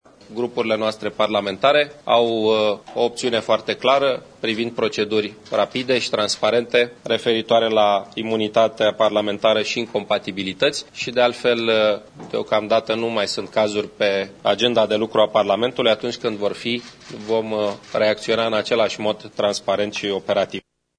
La ieşirea din şedinţa Comitetului, premierul Ponta a explicat că s-a discutat şi aplicarea procedurilor rapide şi transparente ce vizează imunitatea parlamentară: